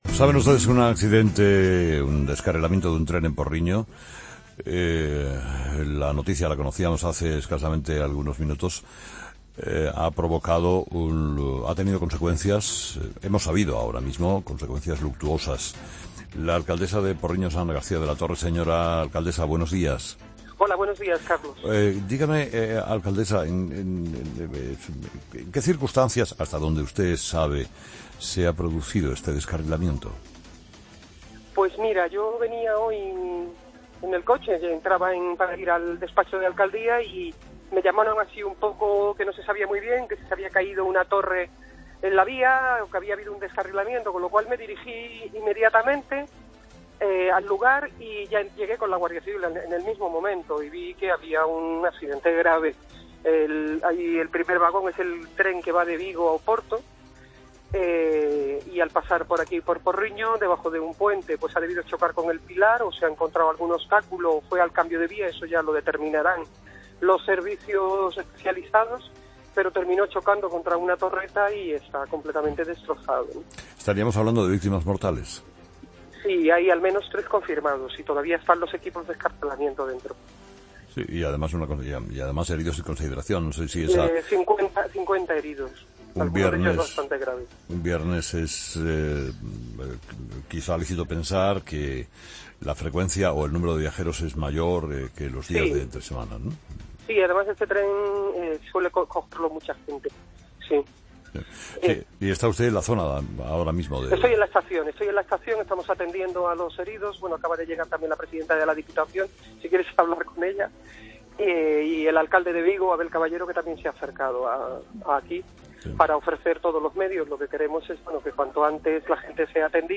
Escucha la entrevista a la alcaldesa de Porriño, Eva García de la Torre, en 'Herrera en COPE'